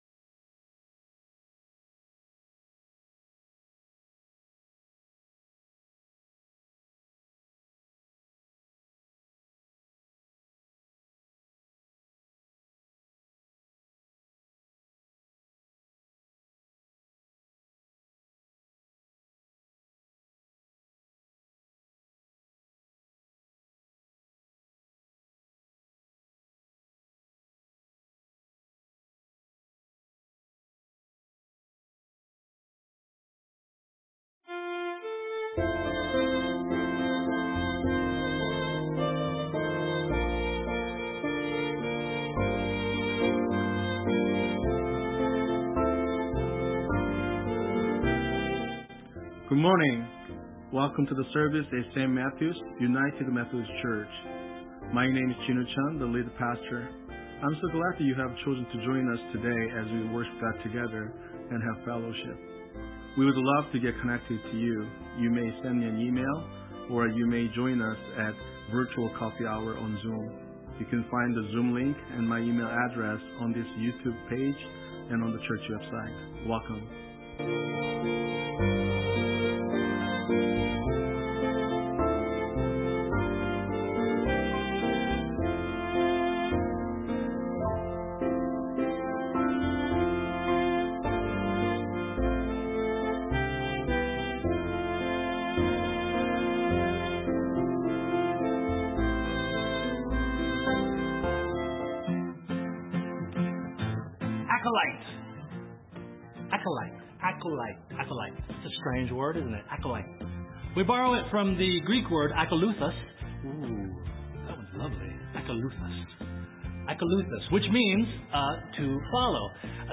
Sermon:America, We Are the Temple of God.
Sunday Worship Service
2021 We are RECEIVED as the Beloved of God The actual worship service begins 15 minutes into the recordings.